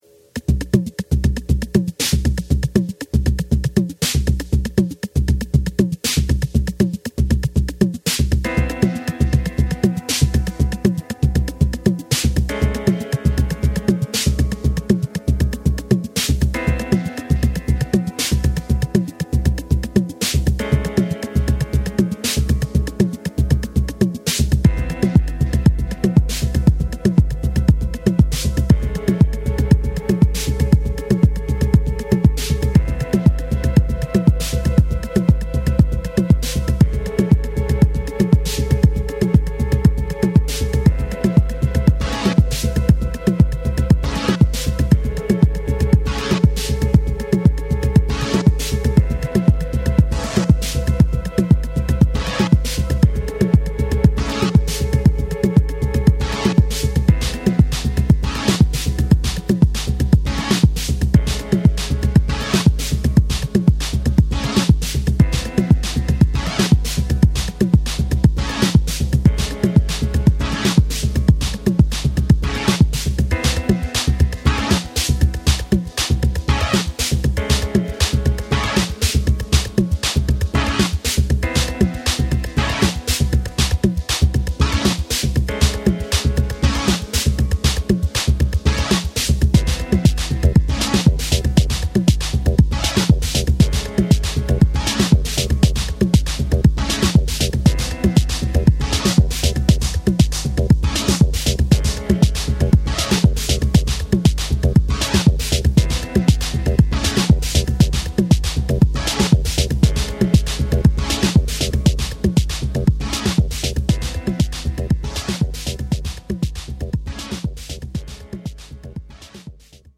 2 deep excursions, both tech house masterworks